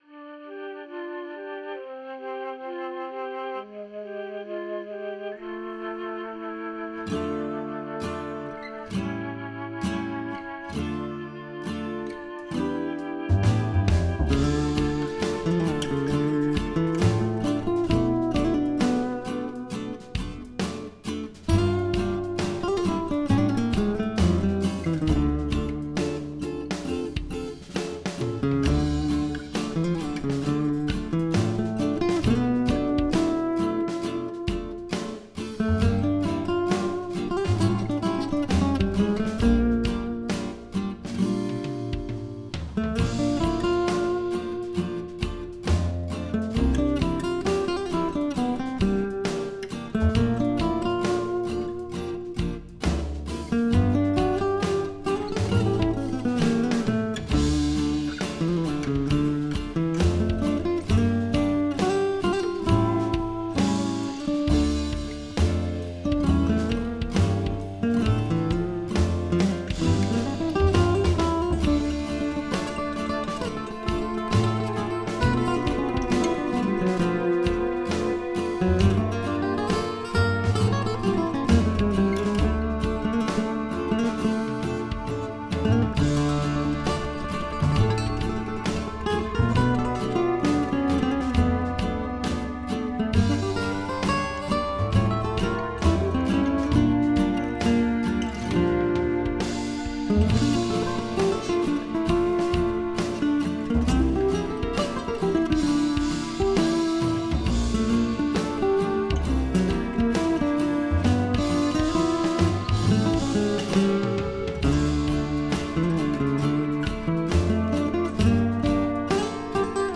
pariah-blues.wav